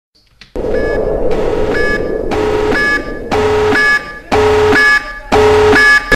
AlarmLoop
alarmsample.mp3